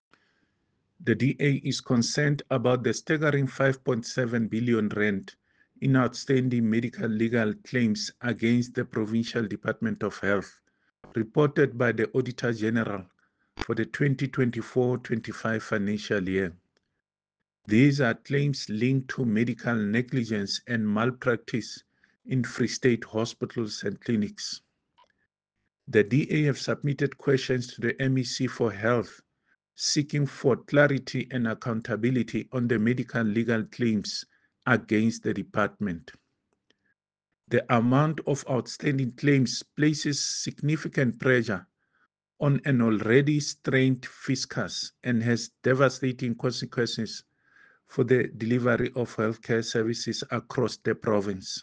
Issued by David Masoeu – DA Spokesperson for Health in the Free State Legislature
Sesotho soundbites by David Masoeu MPL and